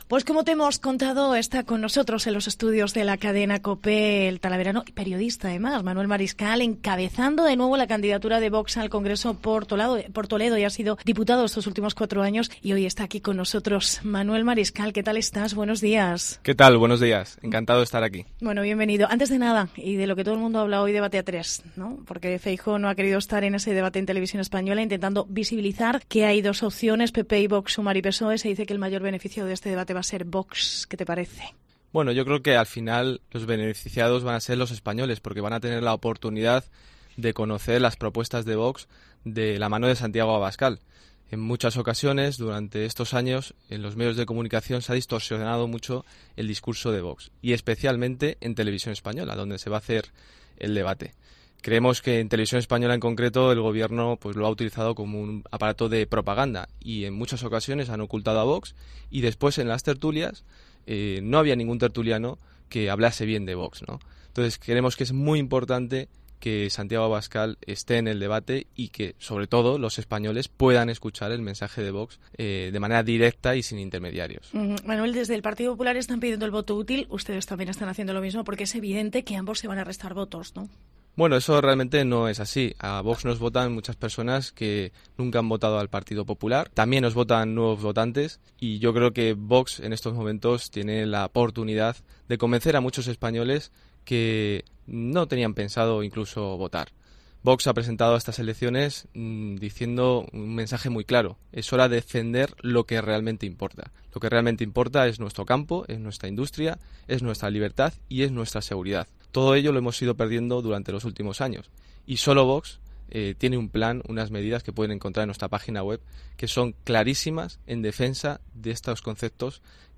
ENTREVISTA
El talaverano Manuel Mariscal, exdiputado los últimos 4 años y cabeza de lista de VOX por Toledo al Congreso, ha estado esta mañana en Herrera en COPE Toledo analizando la actualidad política.